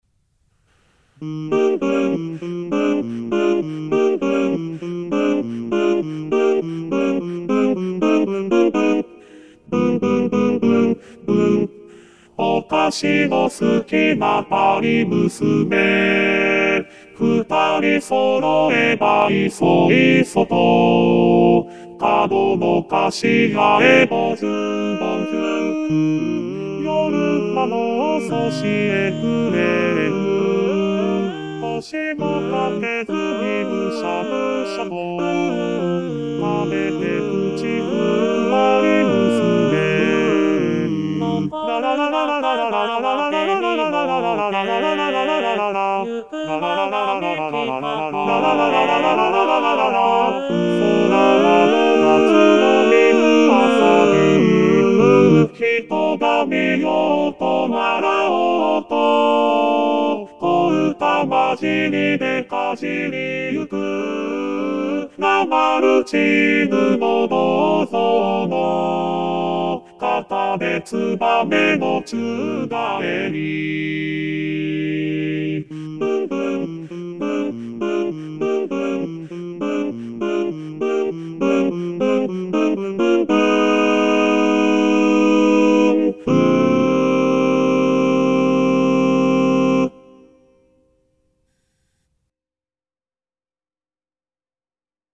★第１２回定期演奏会　演奏曲　パート別音取り用 　機械音声(ピアノ伴奏希望はｽｺｱｰﾌﾟﾚｱｰsdxで練習して下さい)